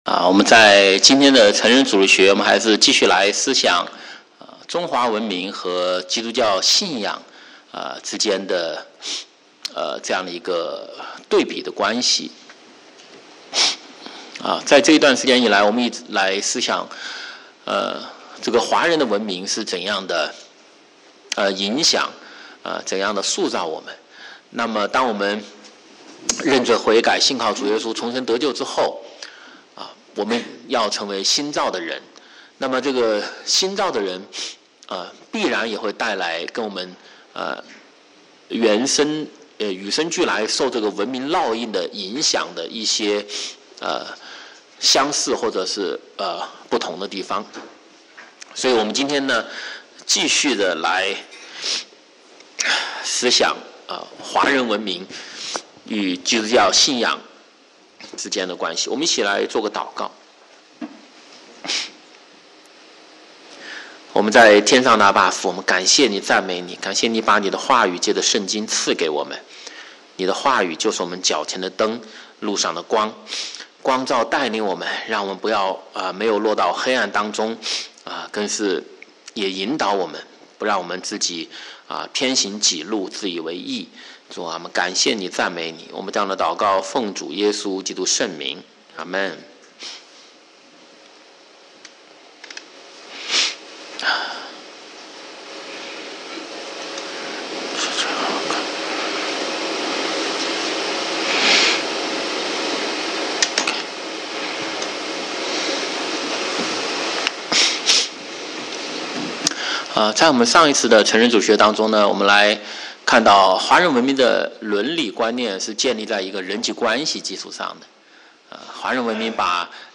华语主日崇拜讲道录音